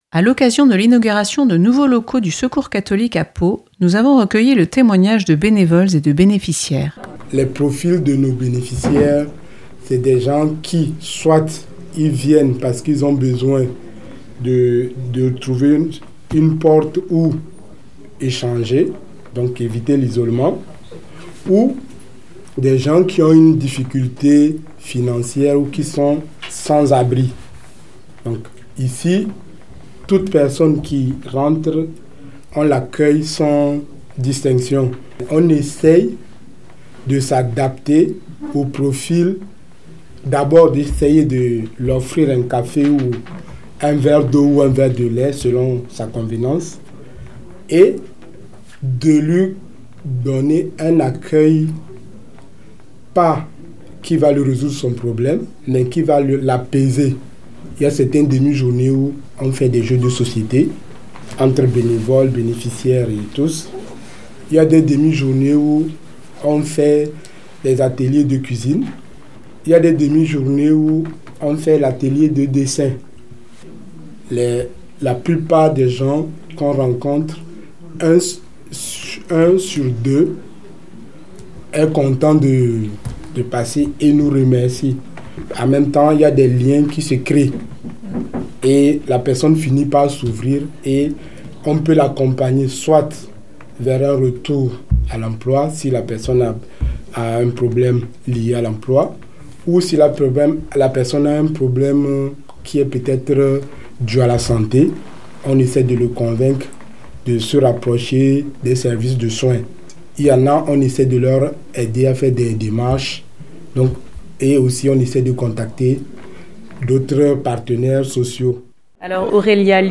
Témoignages recueillis lors de l’inauguration d’un nouveau local à Pau. Bénévoles et bénéficiaires témoignent du besoin de relation et de fraternité des personnes.